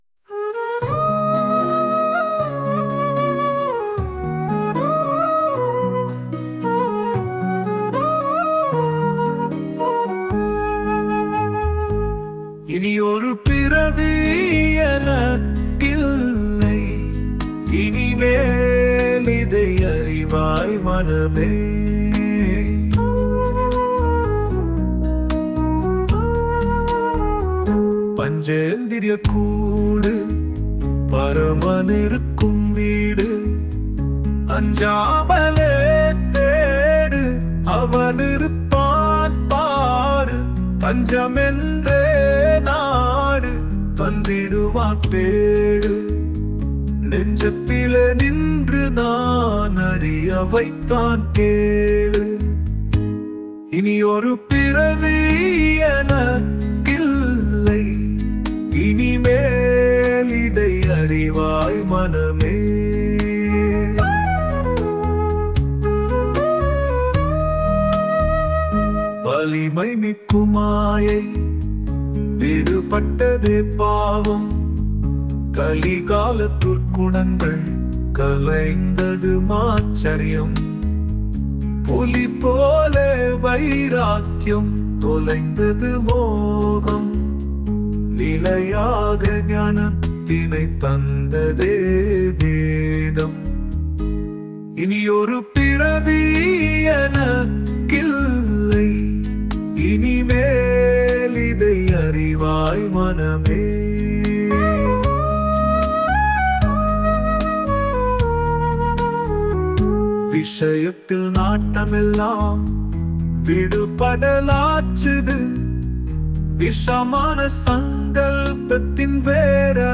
Posted in With AI Audio, பாடல்கள், வேதாந்தக் கவிதைகள்
இசை, குரல்: AI Technology